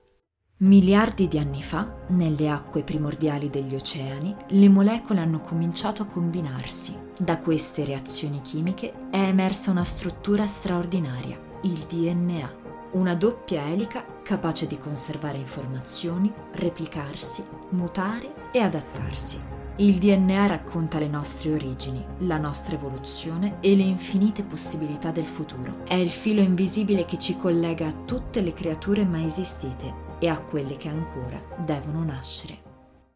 Kommerziell, Tief, Vielseitig, Freundlich, Warm
E-learning